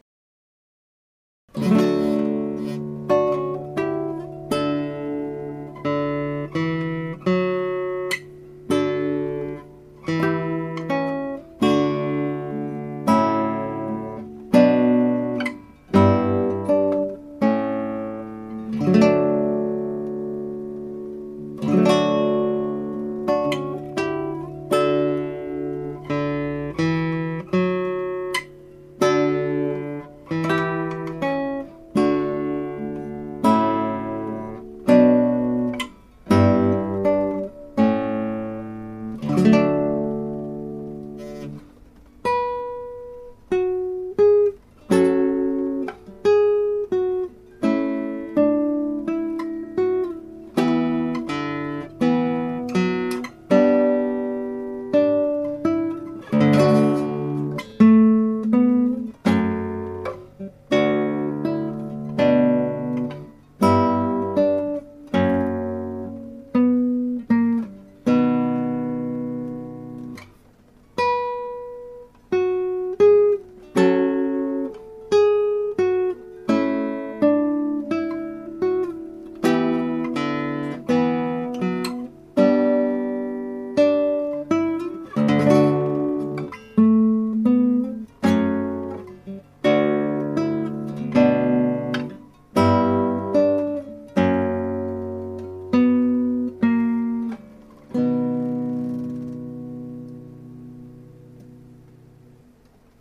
Guitar amatuer play
aria1_6pieces_lute.mp3